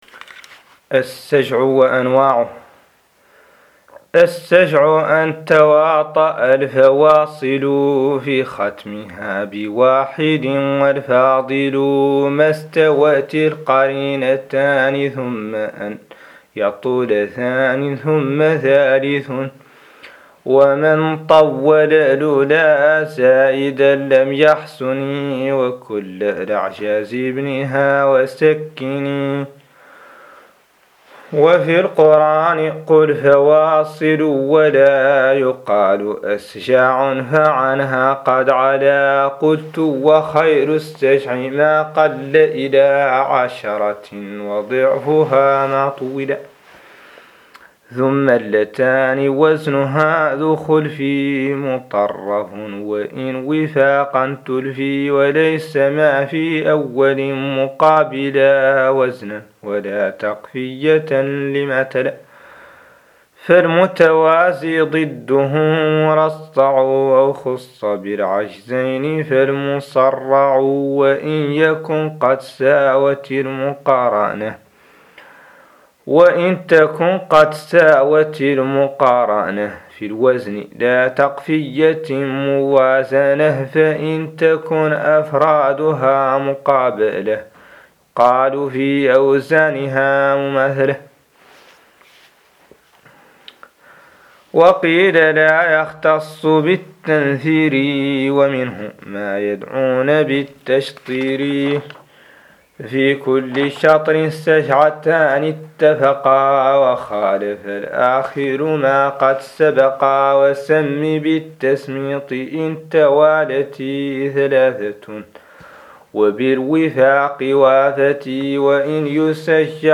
قراءة نظم عقود الجمان للسيوطي 05
ouqood-Juman-Reading05.MP3